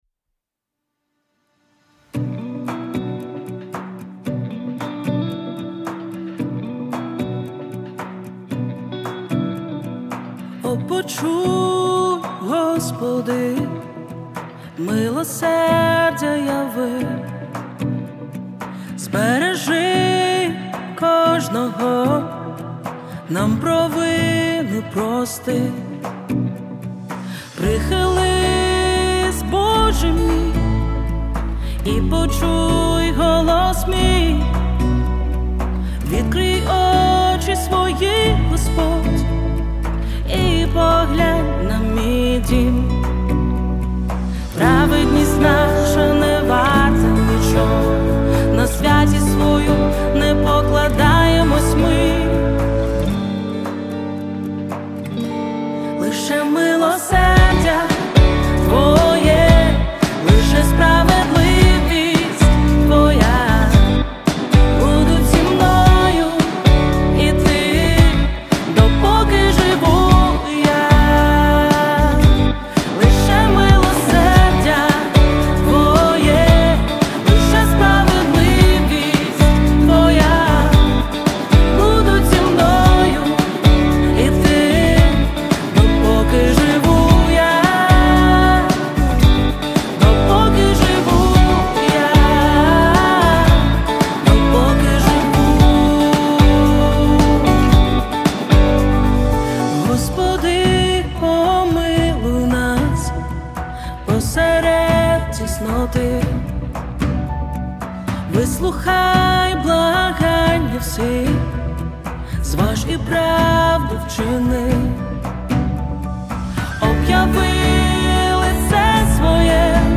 280 просмотров 169 прослушиваний 47 скачиваний BPM: 113